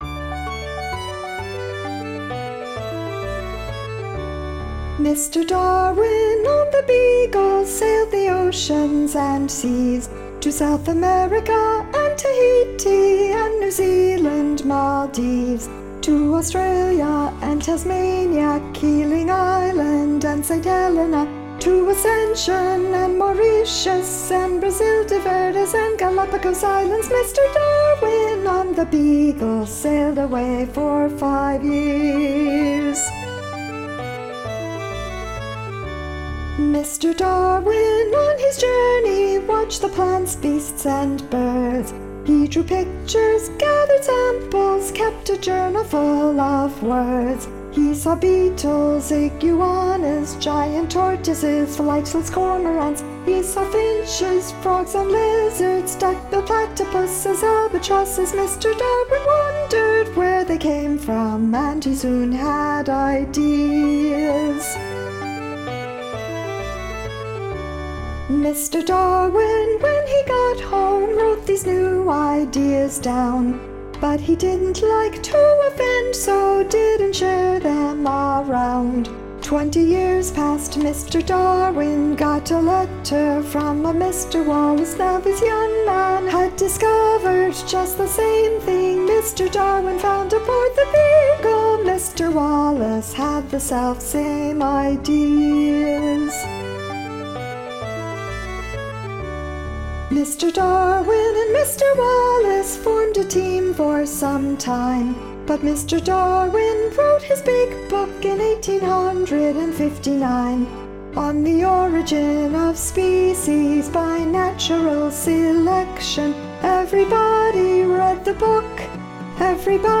• Mr. Darwin, Mr. Wallace, Mr. Matthew - The journeys of English naturalist and geologist Charles Darwin (1809 - 1882) aboard the Beagle are celebrated, appropriately enough, by this sea shanty.